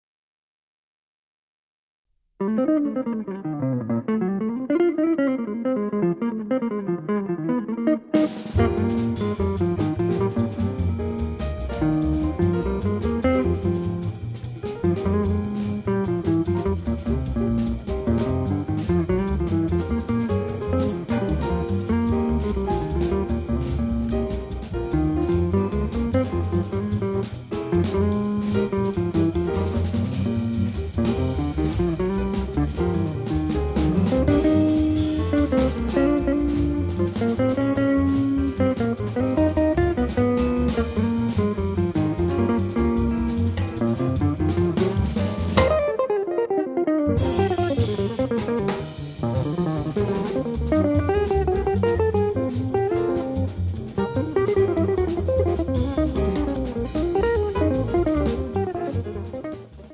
chitarra
pianoforte
contrabbasso
batteria
la swingante